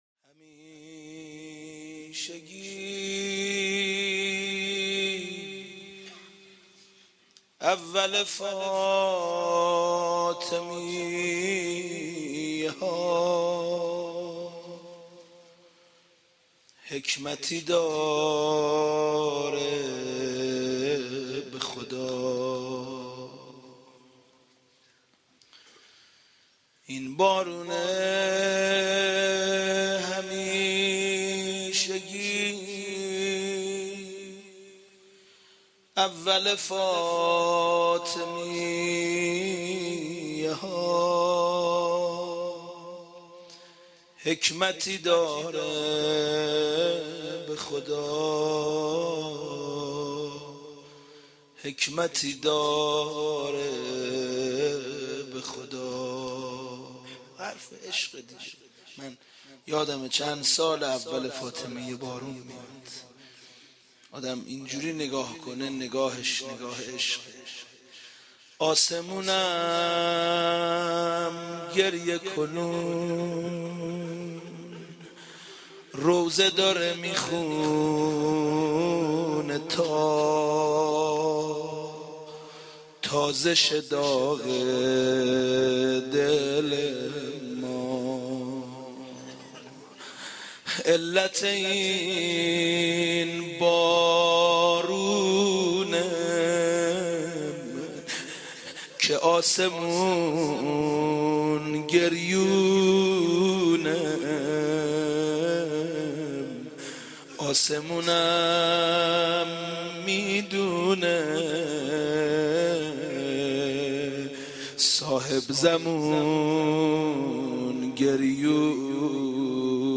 دانلود مداحی داغ دل ما - دانلود ریمیکس و آهنگ جدید
[روضه، شهادت حضرت زهرا(س)]